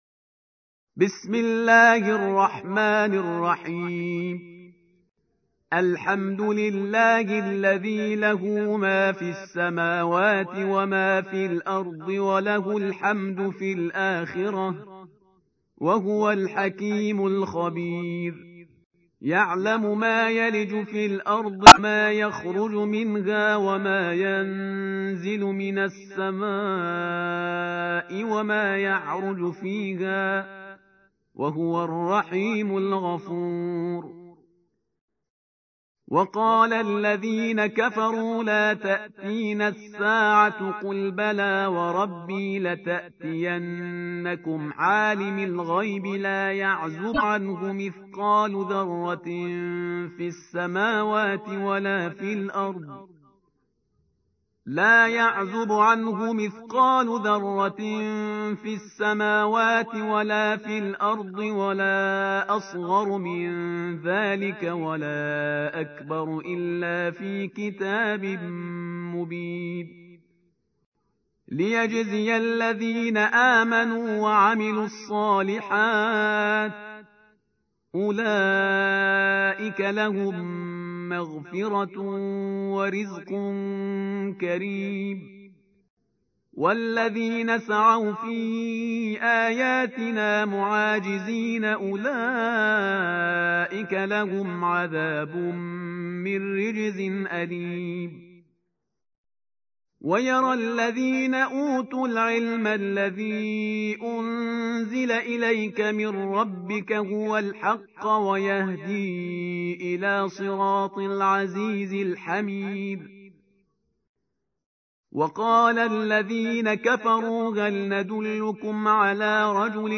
تحميل : 34. سورة سبأ / القارئ شهريار برهيزكار / القرآن الكريم / موقع يا حسين